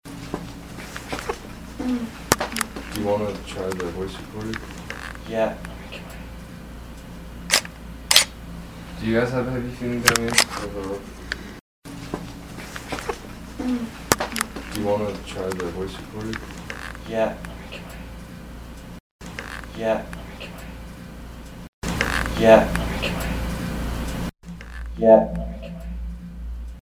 Since it is so clear, the original is repeated twice. I then clip it down to just the EVP, and finally apply noise reduction to it. This is what I would consider a class A EVP.